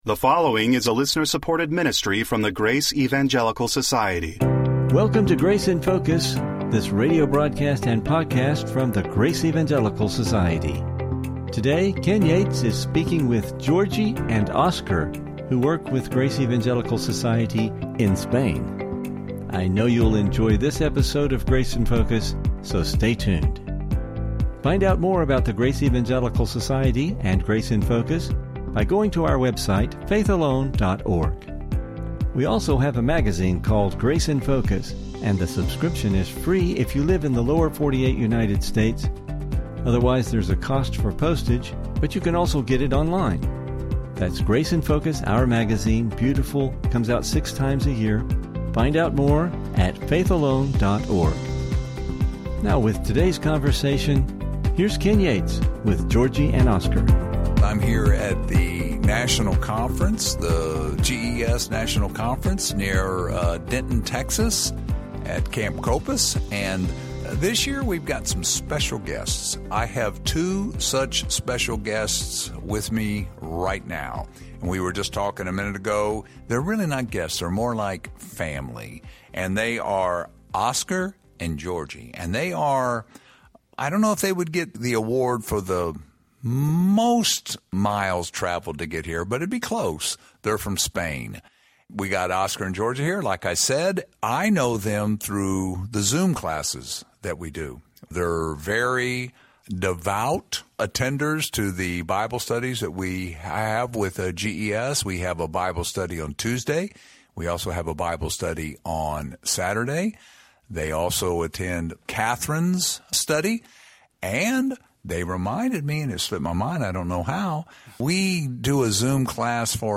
How is the Free Grace message spreading in Europe and other nations? You will find this interview informative.